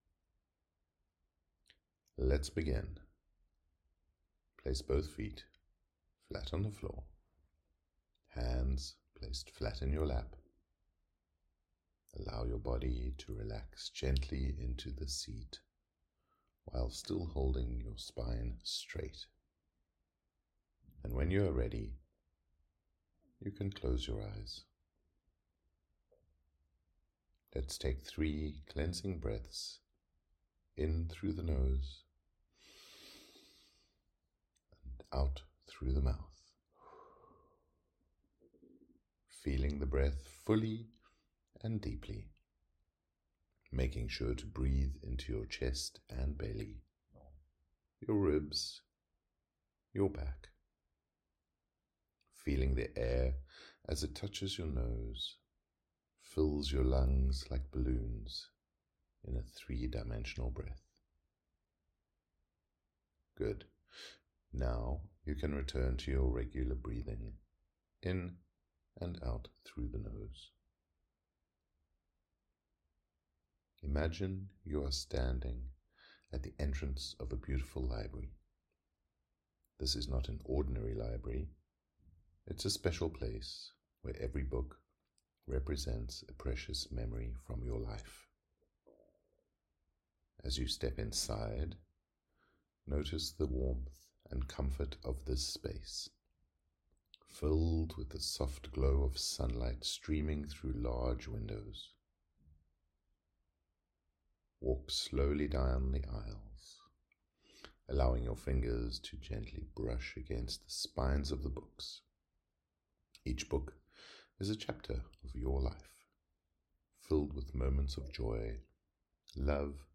Keepsake Meditation
WS13-Meditation-KeepsaKe.mp3